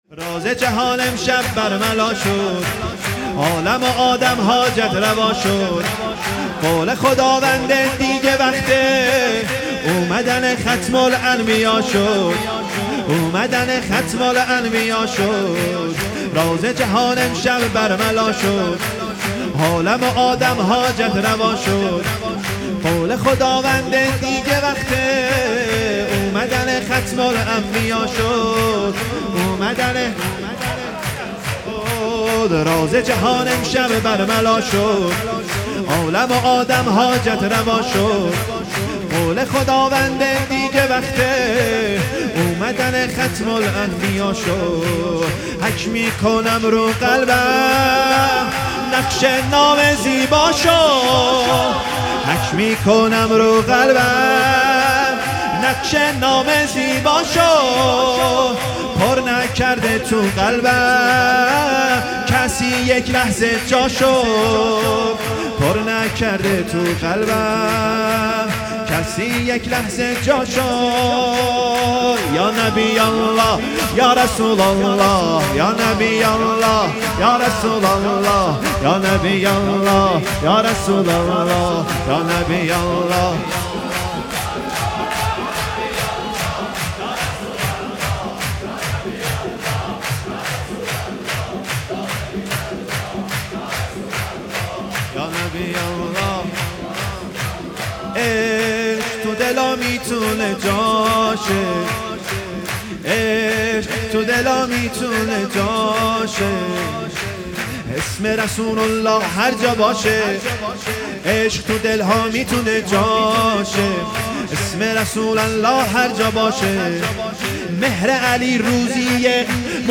شور - راز جهان امشب برملا شد